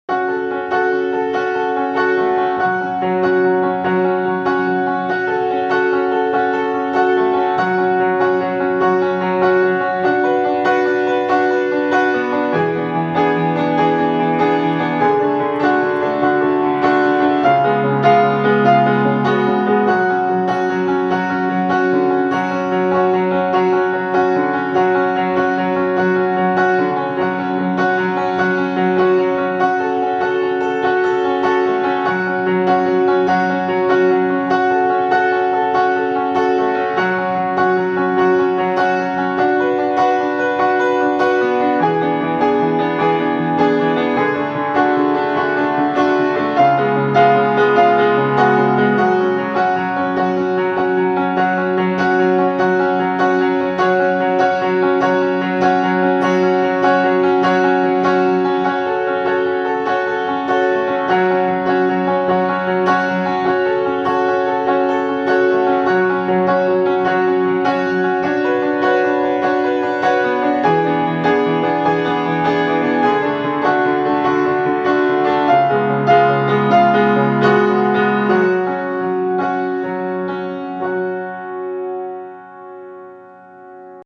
Ode to teddybear - piano This forgotten song is for all the teddybears in the world who knows what’s going on in a traumatised child at night. You’ll hear my old piano in my living room, my fingernails on keys, some other lo-fi sounds and my gratitude to this stuffed animal who comforts children, keeps secrets and gets wet from tears. The recording was made for my memory so that I would finish the song later on.